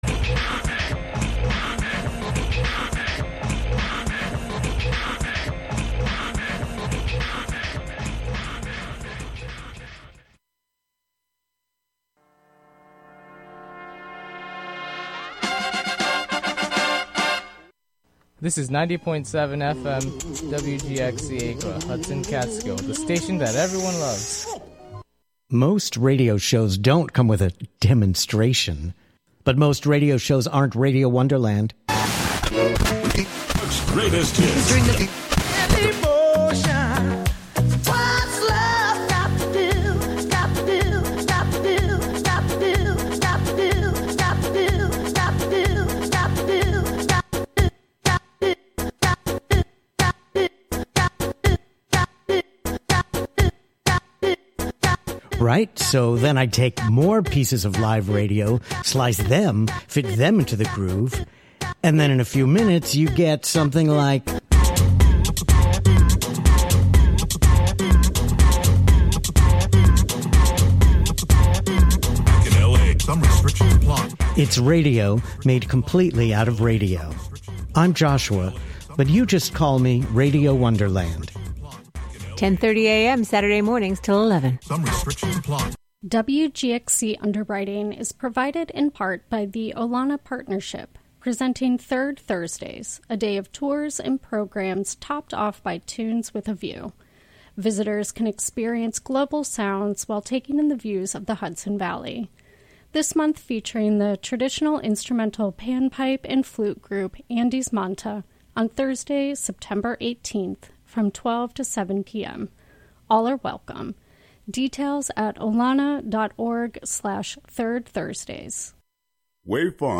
Tune in to this quarterly broadcast composed of local sound ecology, observational narration, and articulations of the mechanical components of the M49; its meanings and purpose reshaped as it traversed the machine of the road to arrive at its site in the forest of Wave Farm.